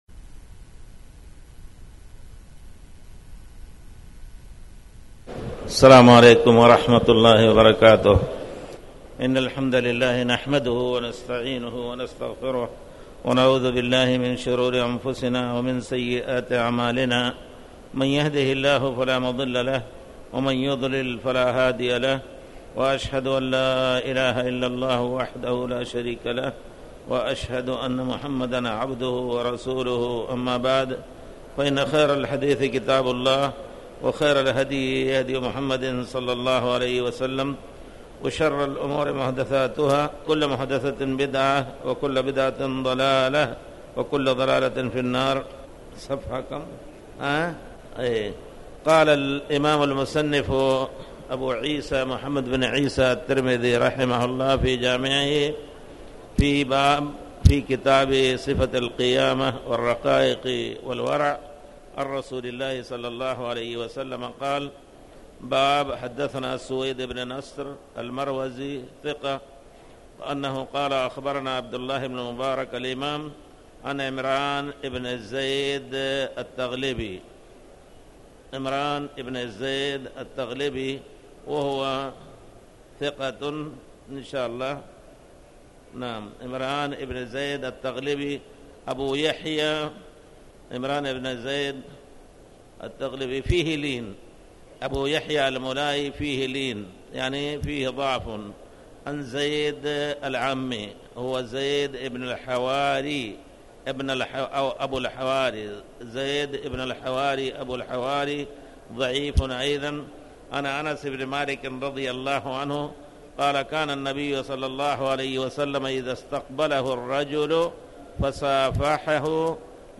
تاريخ النشر ٢٠ جمادى الأولى ١٤٣٩ هـ المكان: المسجد الحرام الشيخ